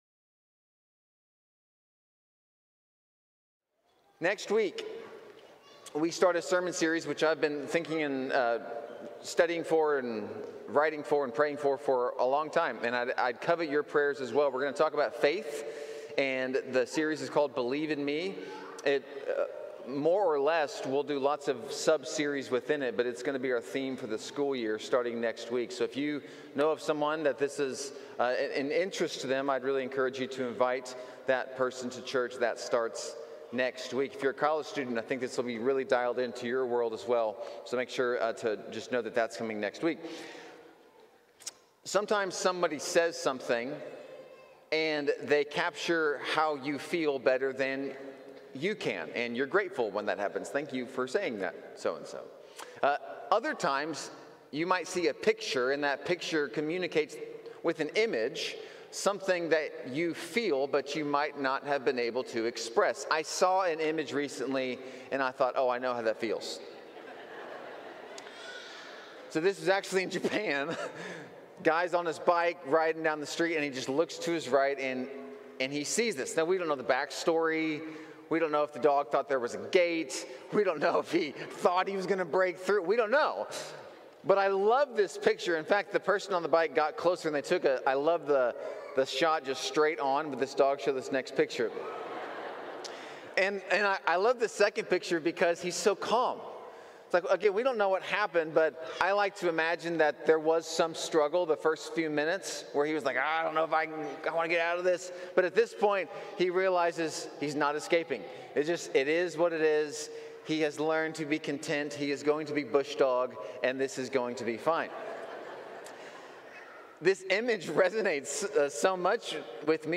Series: Stand Alone, Sunday Morning